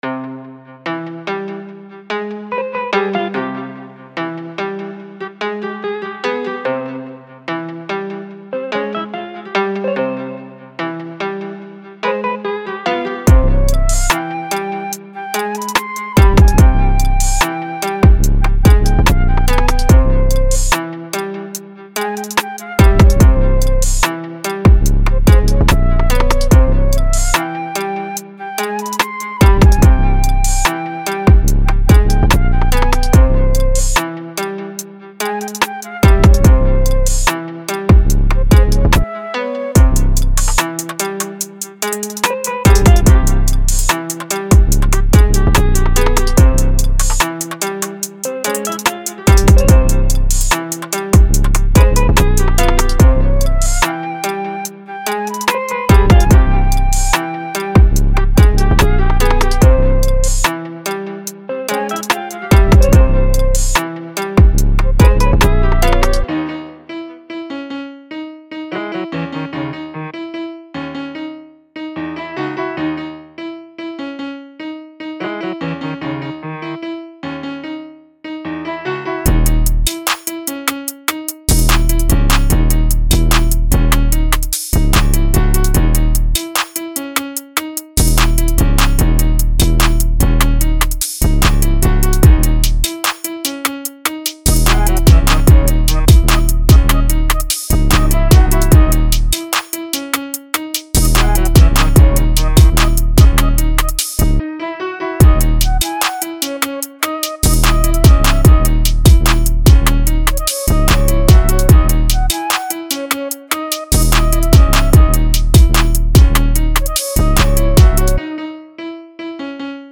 您会发现trunk啪作响的旋律模式，pump打的节奏，高节奏的踩hat，清晰的军鼓
和鼓掌，808s，长笛，吉他，现代钢琴键，琴弦，合成器和高音鼓。
-5个混合和精通的演示